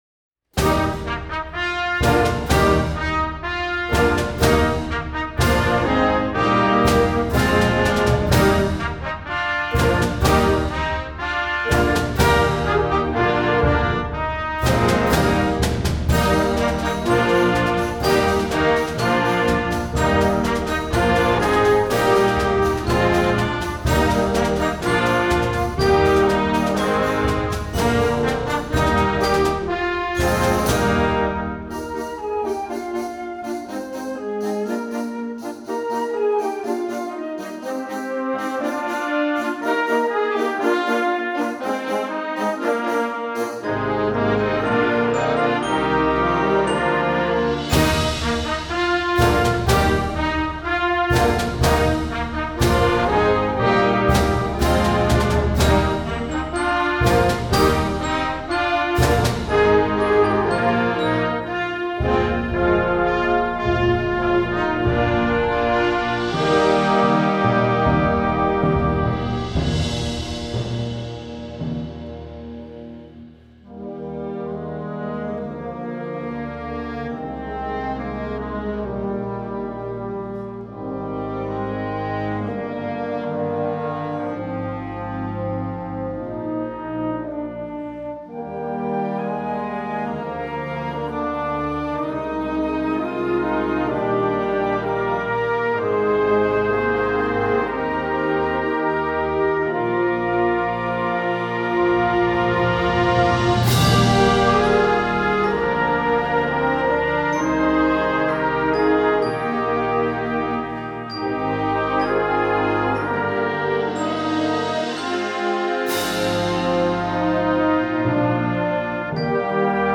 Instrumental Concert Band Concert/Contest
Concert Band